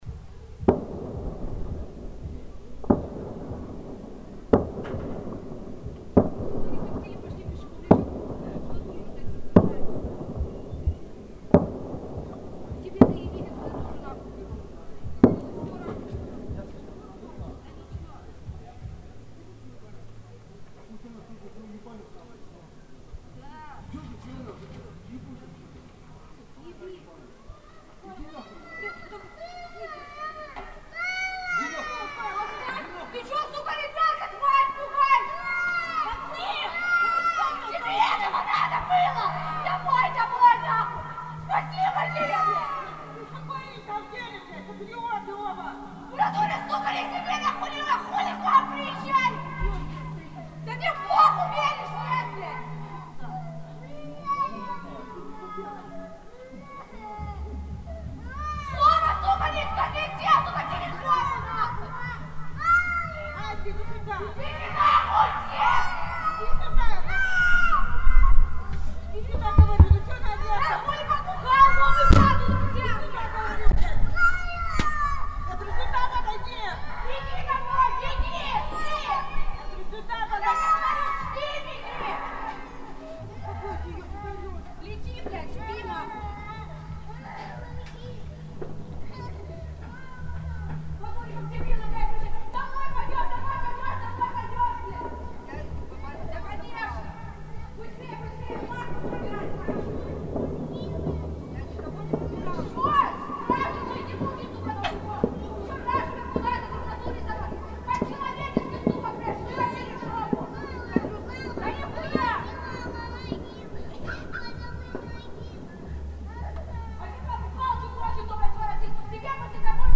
Начало записи - 20-30 секунд после полуночи. Экзистенции провинциального нового года.